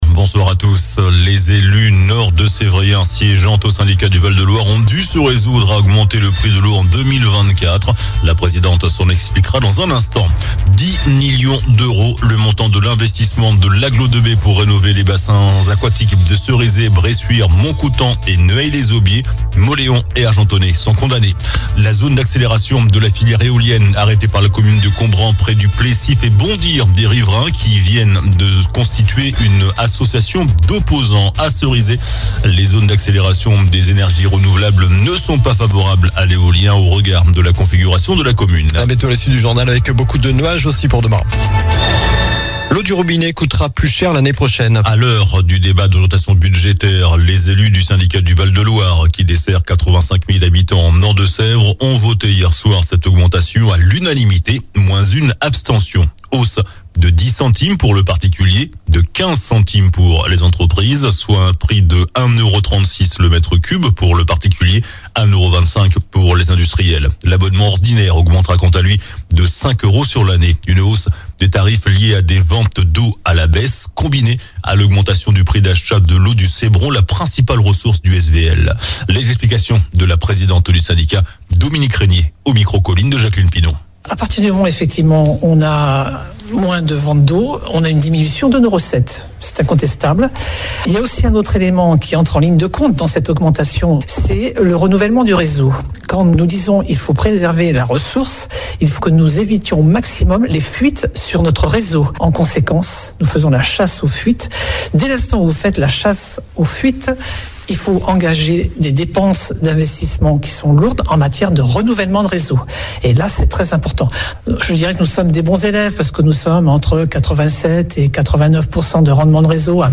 JOURNAL DU JEUDI 21 DECEMBRE ( SOIR )